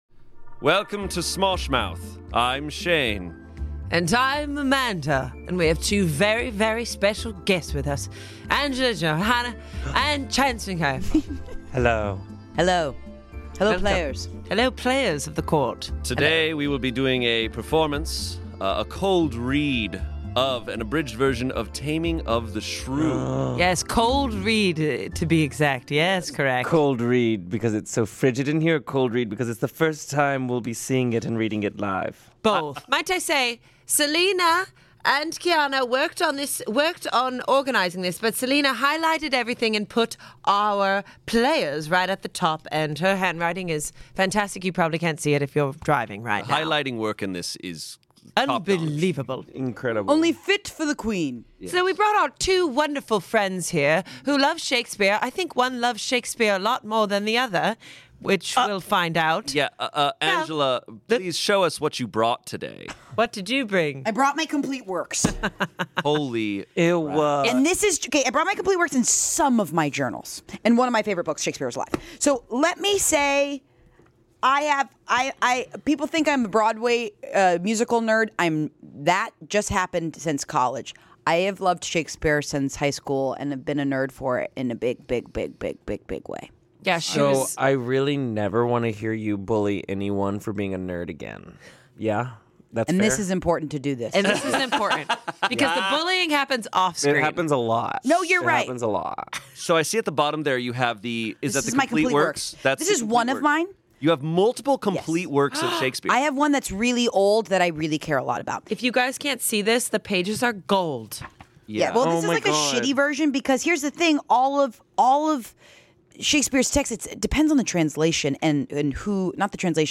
The theatre kids have escaped and got the cheerleader and the jock to act out some Shakespeare.
0:00 Intro 10:36 Football Intermission 13:46 The Taming of the Shrew cold read